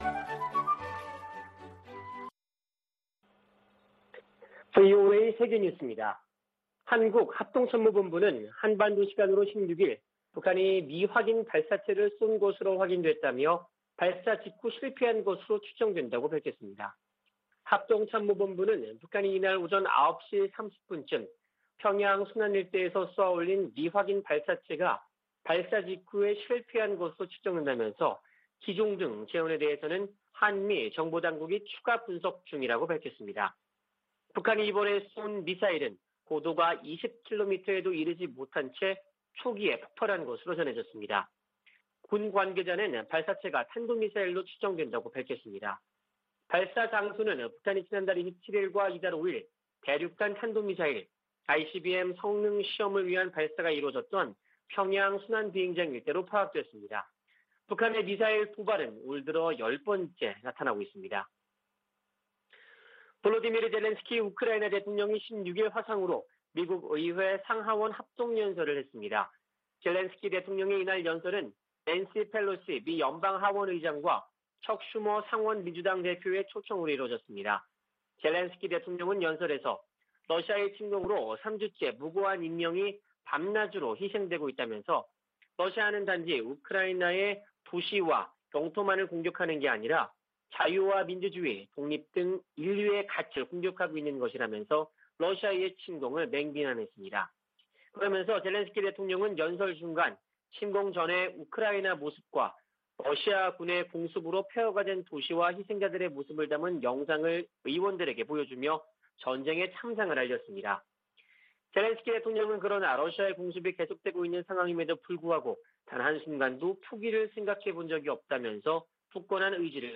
VOA 한국어 아침 뉴스 프로그램 '워싱턴 뉴스 광장' 2022년 3월 17일 방송입니다. 한국 합동참모본부는 북한이 16일 평양 순안 일대에서 미확인 발사체를 발사했으나 실패한 것으로 추정된다고 밝혔습니다. 미 국무부는 북한의 탄도미사일 시험발사를 규탄하고, 대화에 나서라고 촉구했습니다. 유럽연합(EU)은 북한의 최근 탄도미사일 발사와 관련해 추가 독자 제재 부과를 검토할 수 있다는 입장을 밝혔습니다.